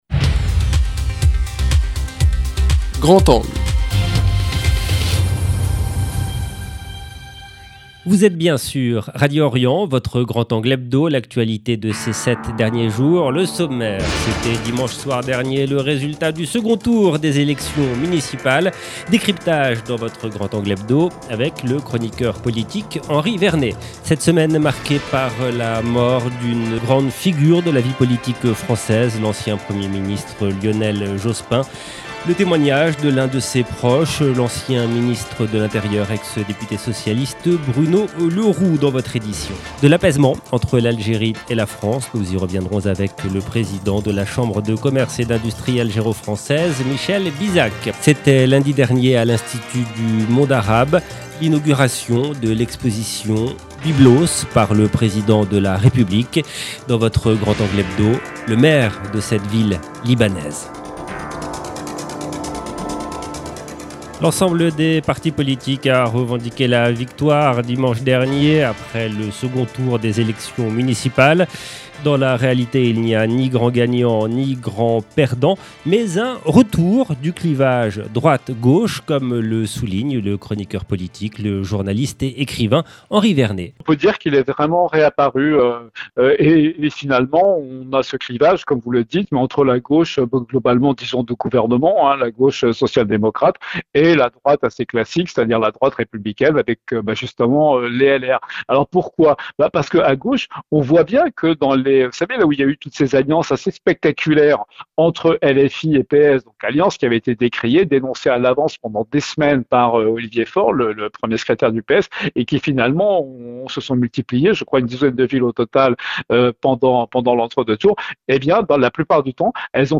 Séquence politique ensuite avec Bruno Le Roux, ancien ministre de l’Intérieur, qui revient sur le parcours de Lionel Jospin, ses réformes à Matignon et les coulisses du 21 avril 2002.